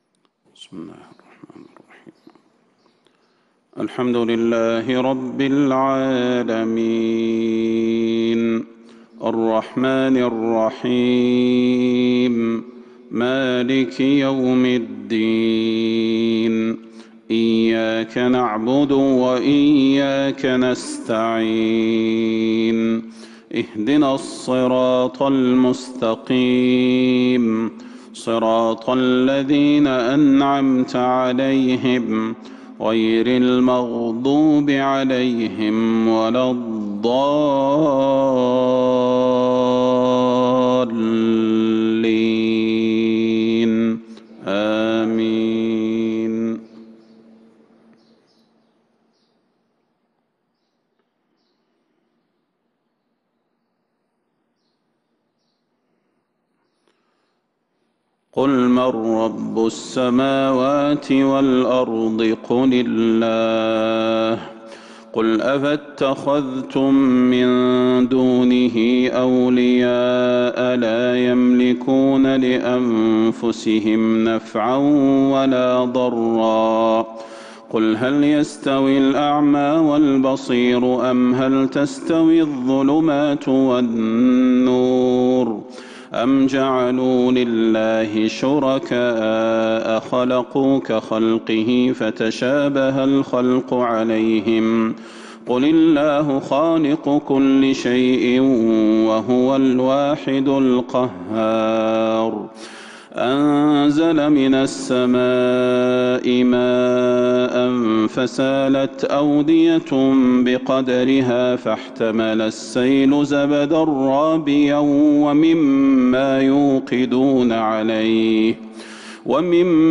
فجر السبت 1-7-1442هـ من سورة الرعد Fajr Prayar from Surah Ar-raad | 13/2/2021 > 1442 🕌 > الفروض - تلاوات الحرمين